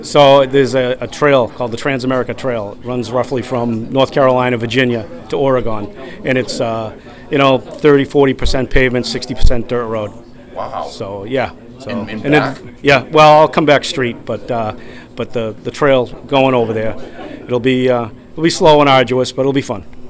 Duxbury Select board member Michael McGee spoke for the board and the town and thanked Chief Nord for his service.